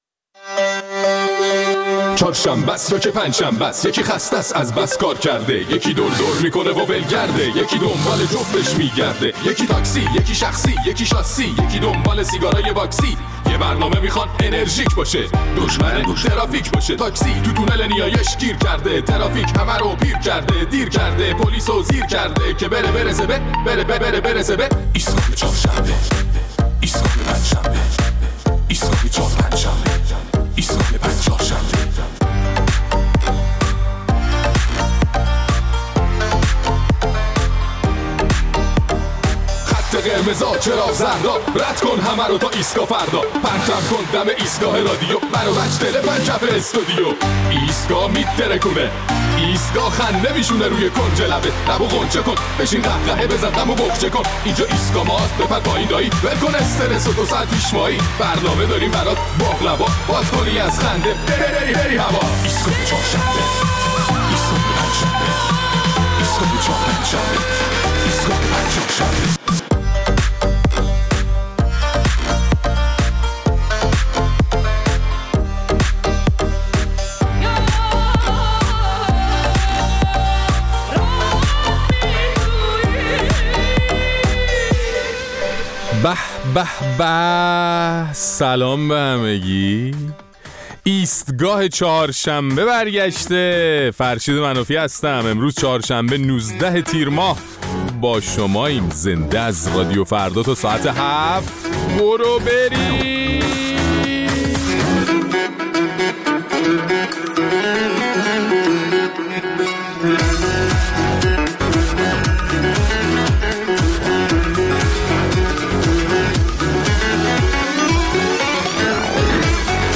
در این برنامه نظر مخاطبان ایستگاه فردا را در مورد درخواست دادسرای ارشاد از مردم برای گزارش دادن موارد منکراتی‌ای که مشاهده می‌کنند، می‌شنویم.